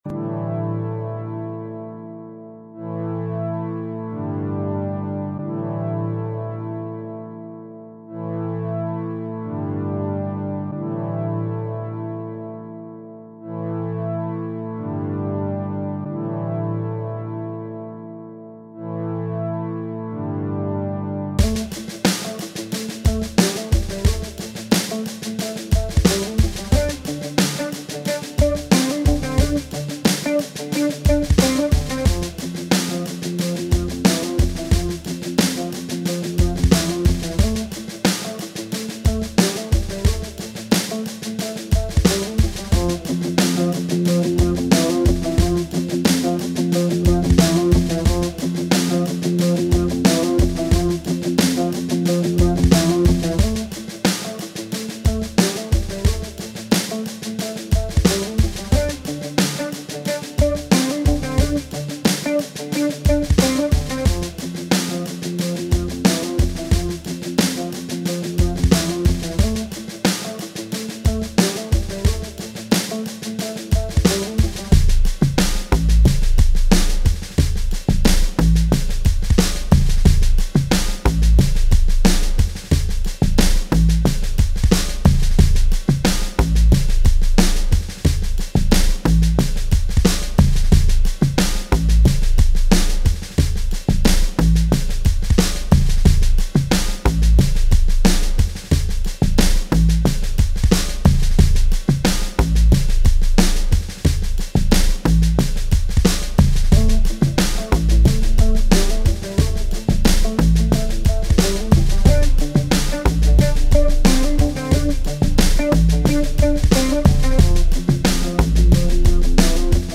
Home > Music > Electronic > Bright > Medium > Laid Back